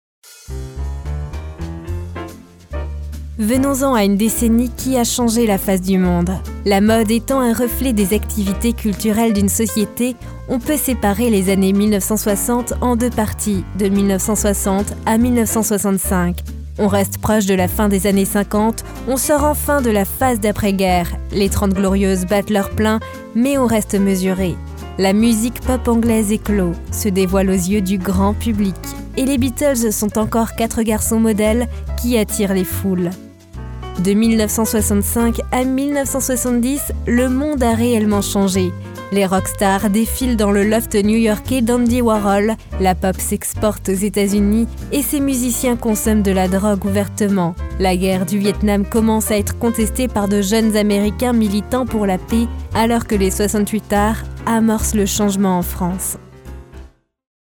Sprechprobe: Sonstiges (Muttersprache):
French Voice Over Talent